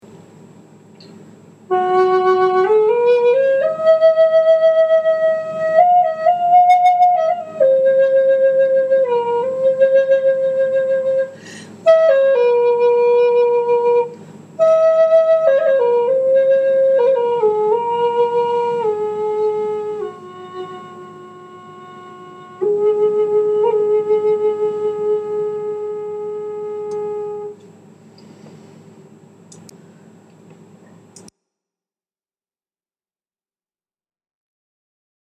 Walnut F# Bear Totem $450.00
F-Bear-Walnut.mp3